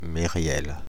Mériel (French pronunciation: [meʁjɛl]
Fr-Paris--Mériel.ogg.mp3